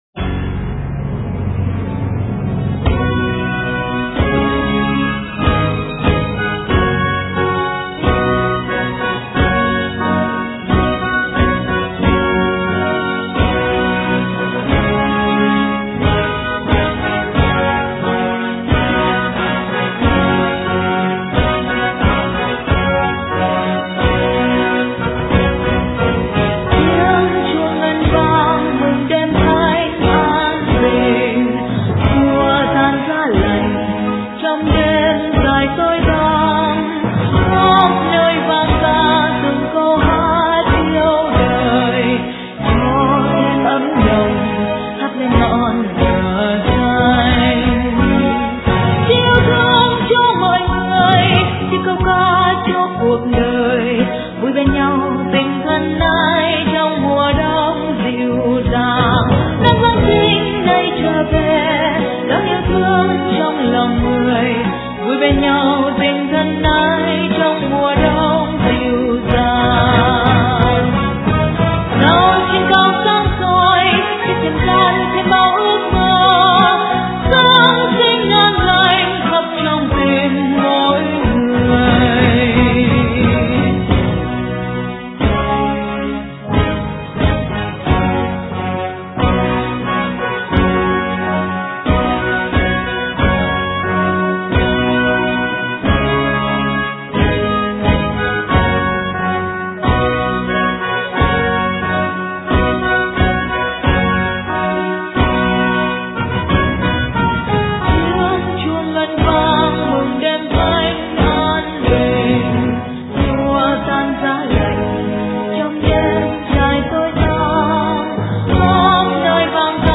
* Thể loại: Giáng Sinh